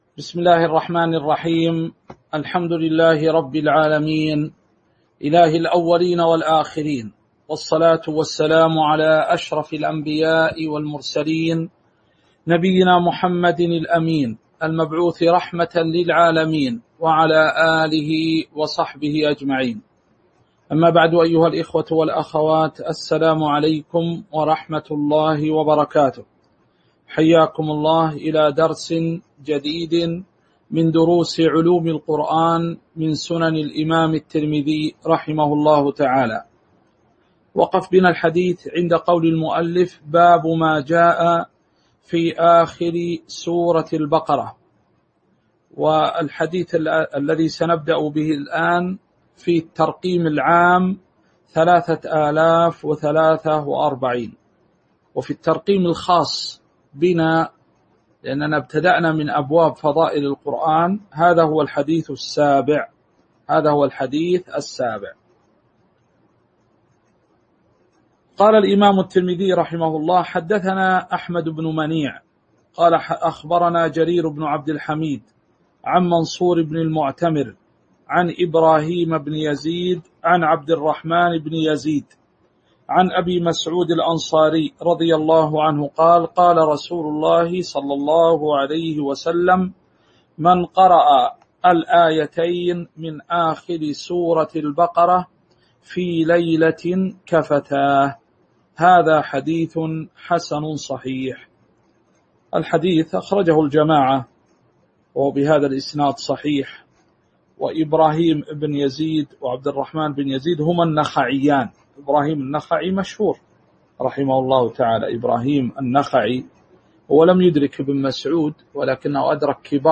تاريخ النشر ١٠ محرم ١٤٤٣ هـ المكان: المسجد النبوي الشيخ